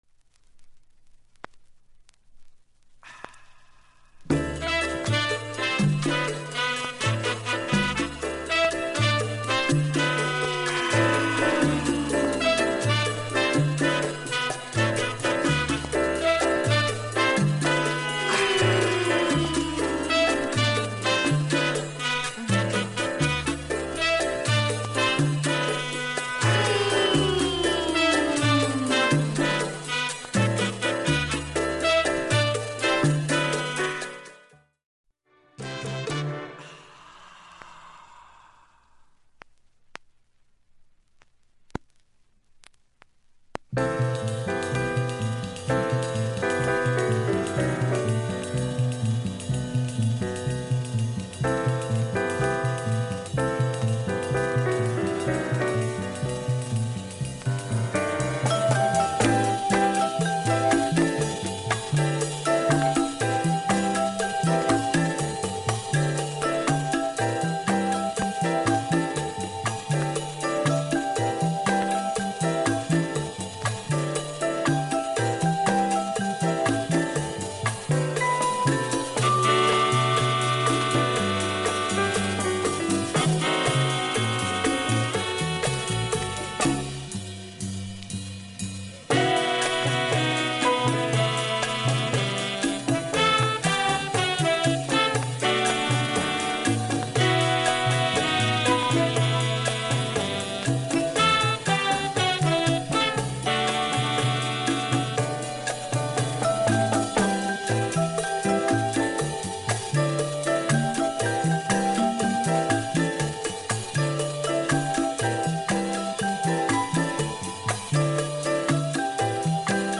A-2始め4ミリのキズでプツ音出ます。
いい音質です。
MONO